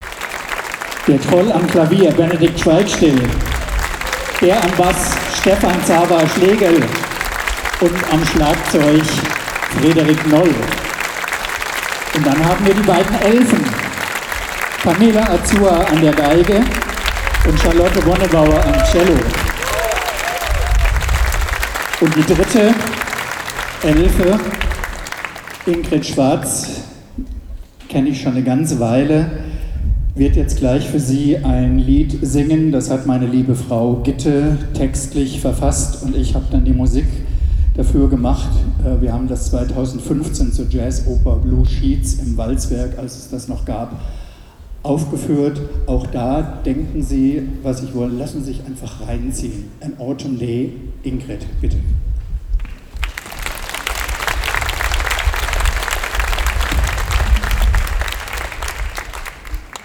Konzert Regionalabend 31. Jazz im Brunnenhof (Trier)
05 - Ansage.mp3